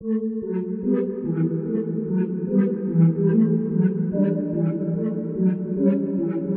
原声钢琴146bpm Fmajor低调
Tag: 146 bpm Pop Loops Piano Loops 4.42 MB wav Key : F